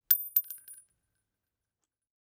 CassingDrop 03.wav